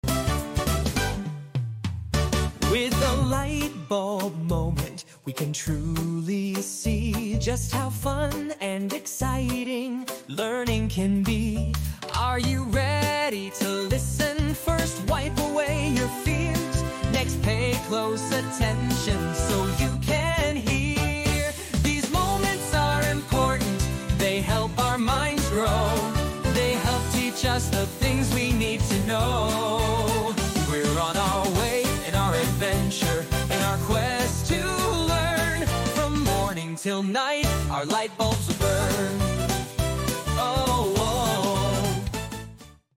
Mp3 Sound Effect 💡 That “I got it!” moment hits different—especially when it comes with a beat!